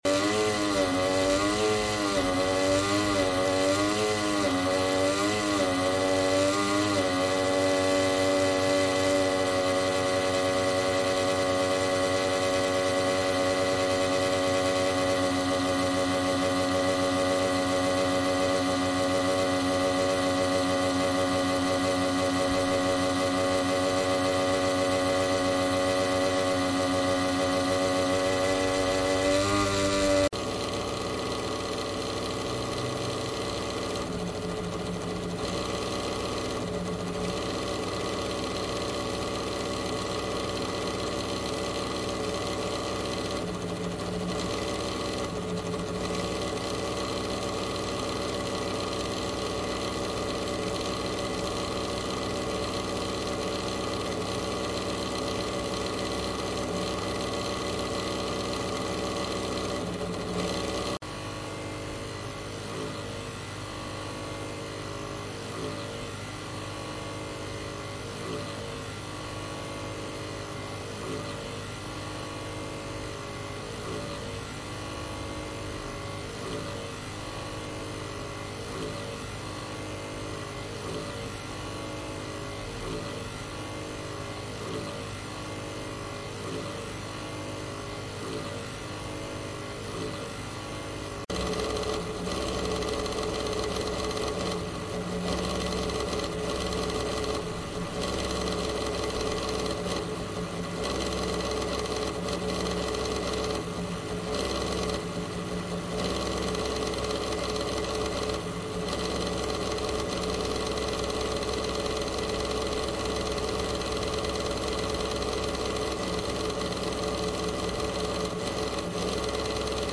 破碎的PC冷却器 4x
Tag: 破碎 损坏 电脑 冷却器